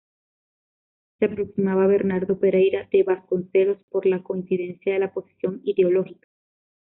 coin‧ci‧den‧cia
/koinθiˈdenθja/